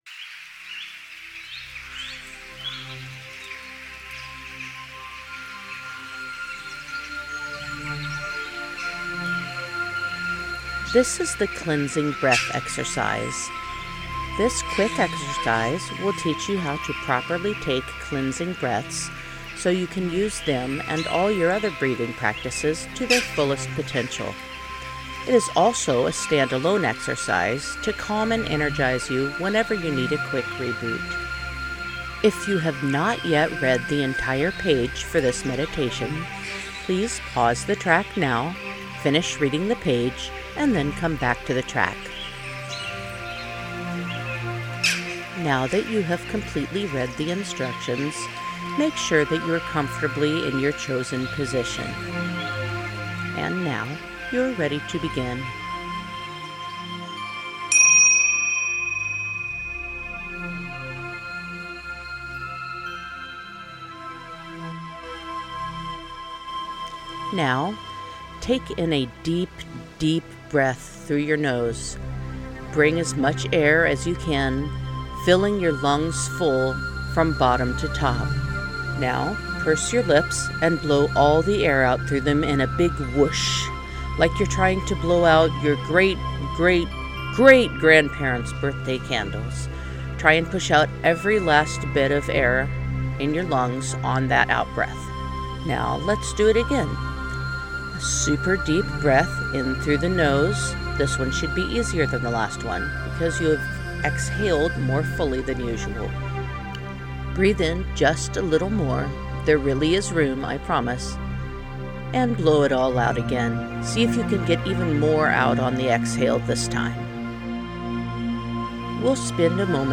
The guided meditation track uses a chime to begin this meditation, as do most of the meditations in this series.
GuidedMeditation-cleansingbreath.mp3